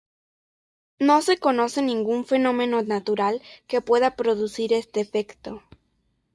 e‧fec‧to
/eˈfeɡto/